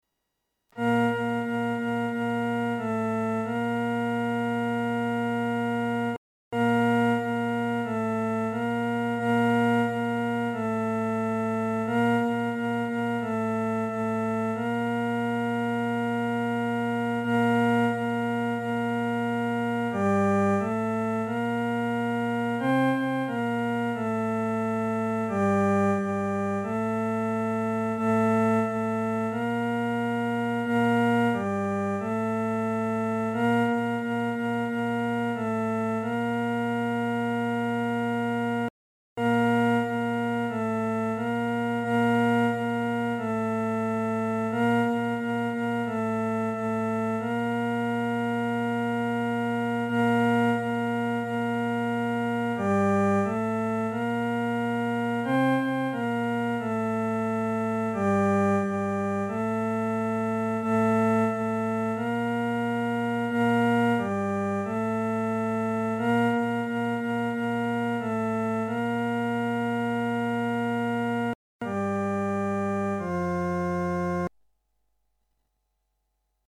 伴奏
男高
曲调很朴素，用的是五声音阶，农村信徒很容易上口。